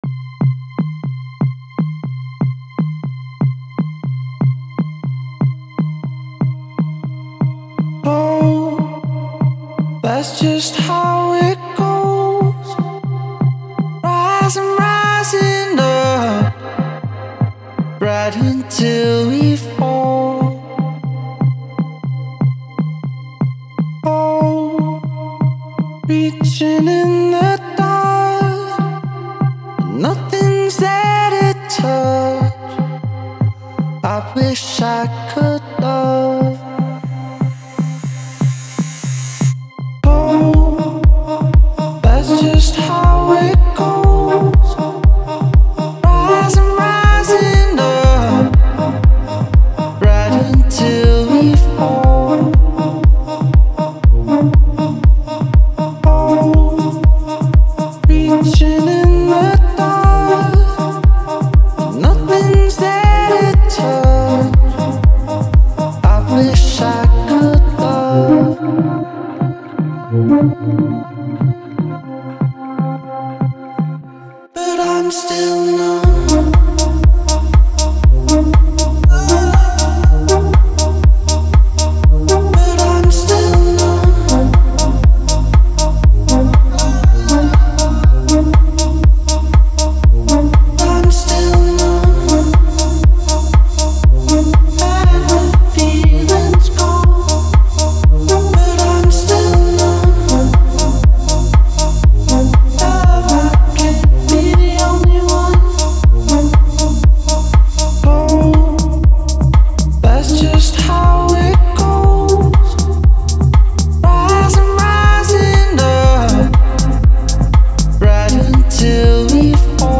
• Жанр: Dance